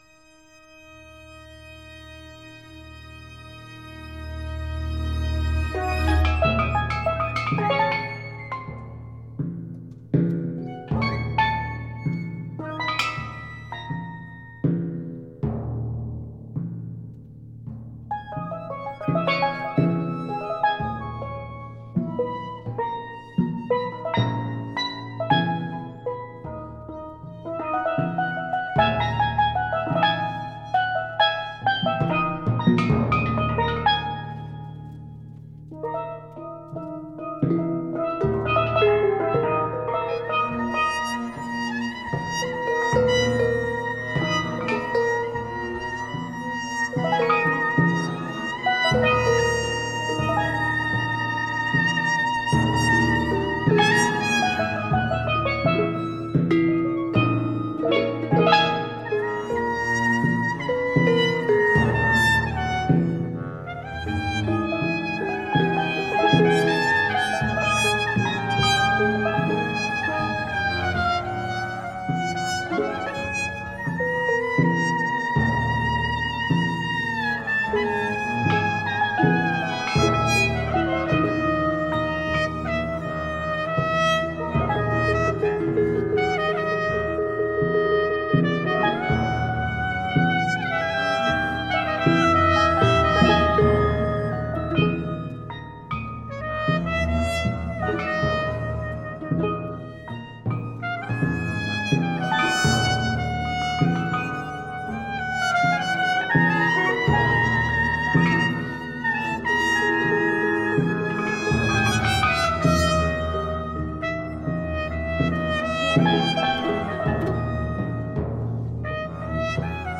pour percussions et ensemble instrumental